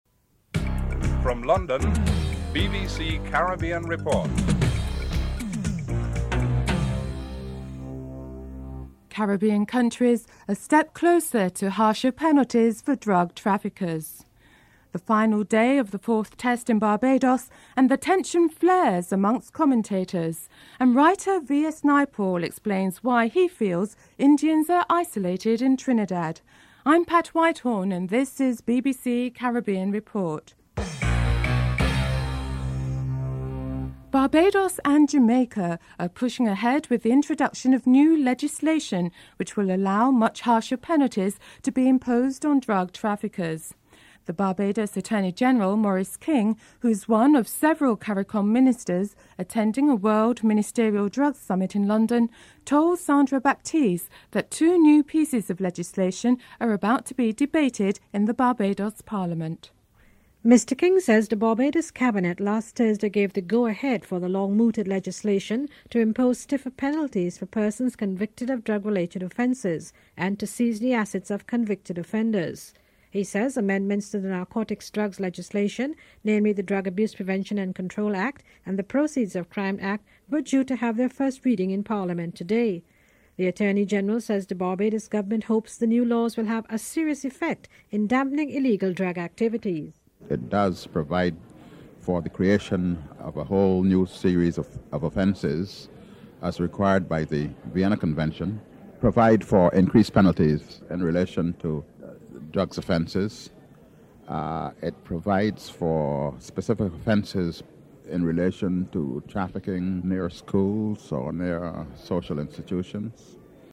Report ends abruptly.